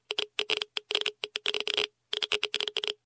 surface_effect_radiaciya.ogg